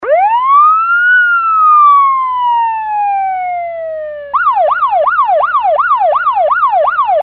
Download this Real Police Siren sound to experience amazing heat of the police chase!
edit: attached an original horn5.raw if you happen to loose your copy, and attached MP3 preview of the siren sound.